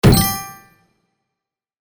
Shield Item RPG 1.mp3